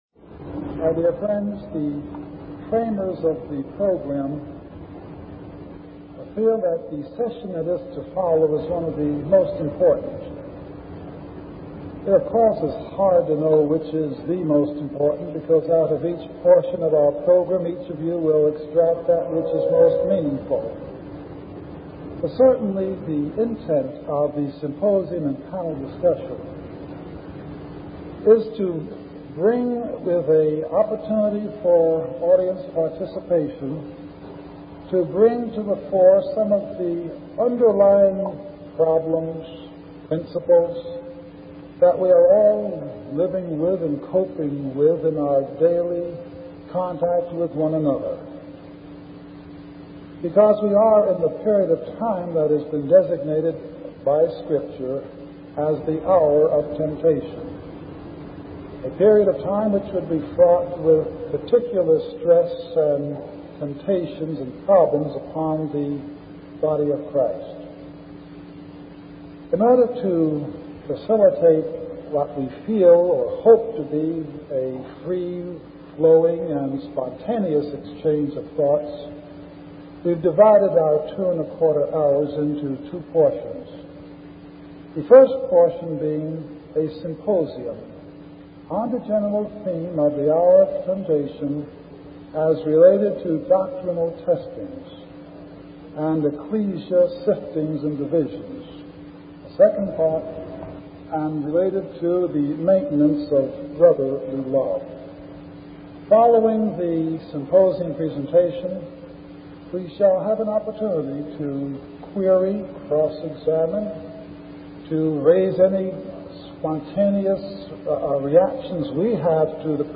From Type: "Symposium"